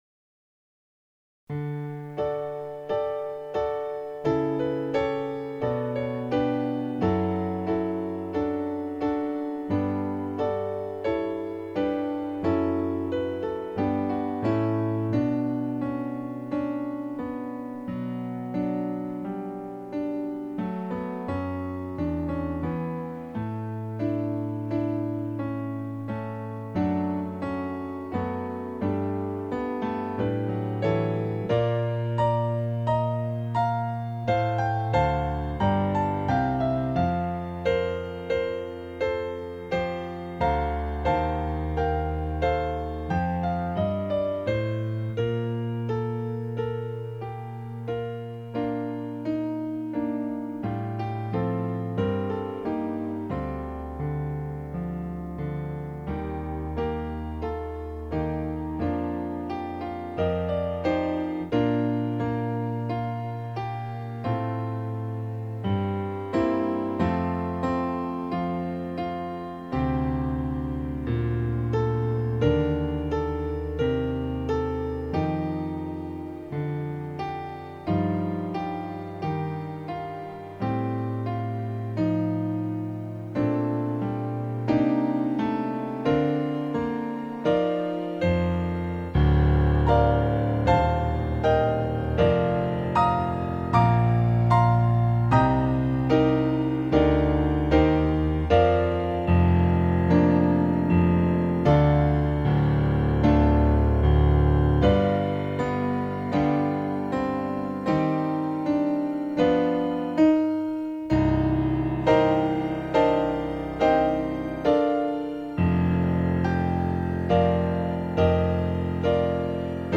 Voicing/Instrumentation: Piano Solo We also have other 10 arrangements of " A Mighty Fortress is our God ".